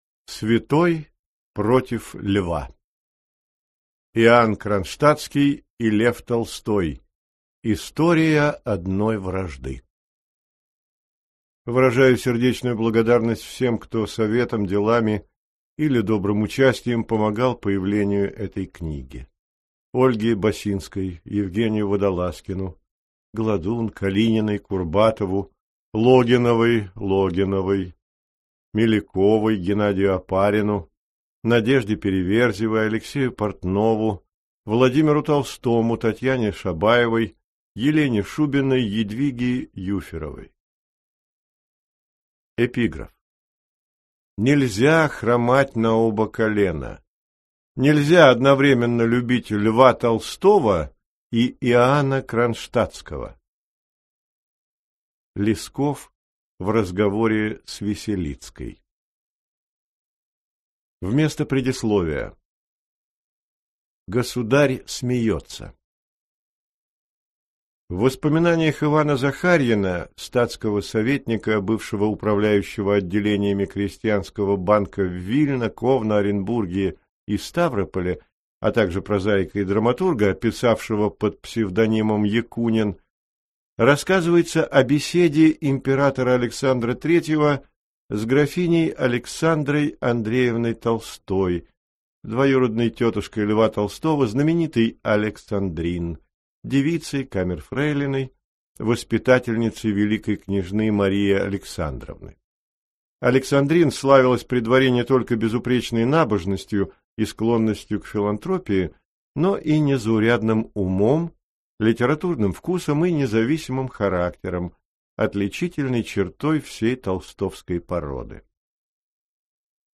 Аудиокнига Святой против Льва. Иоанн Кронштадтский и Лев Толстой. История одной вражды | Библиотека аудиокниг